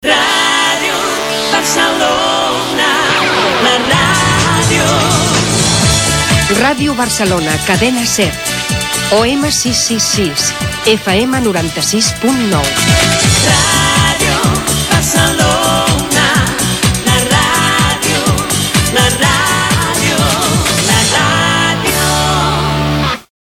Identificació i freqüències en OM i FM